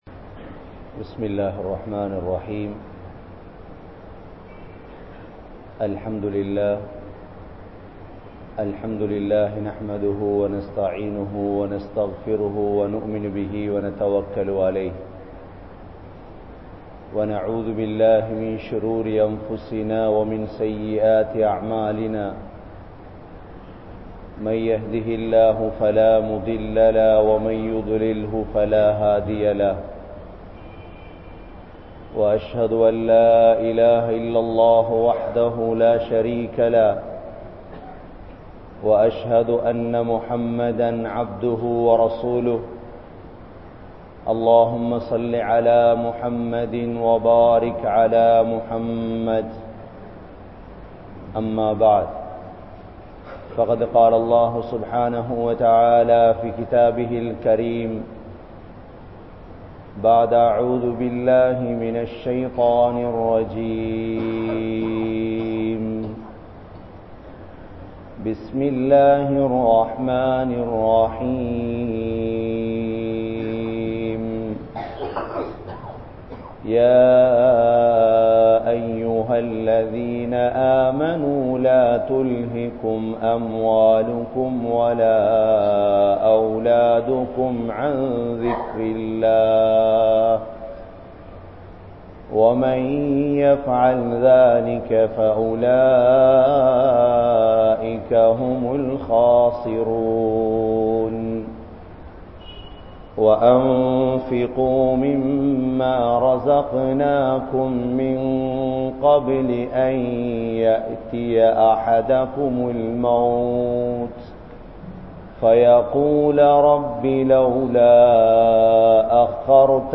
Who Is Intelligent? (புத்திசாலி யார்?) | Audio Bayans | All Ceylon Muslim Youth Community | Addalaichenai
Addalaichenai 03, Grand Jumua Masjith